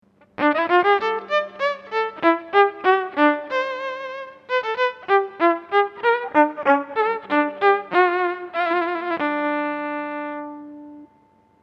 This clip reproduces the calculated string displacement, or motion, resulting from numerical integration of the recording 3B above, representative of the lower range of the instrument. The displacement waveform here is again sawtooth-shaped (Figure 4B). Some reverberation, but no tone filtering, has been added to this recording.
4B_StringDisplacement.mp3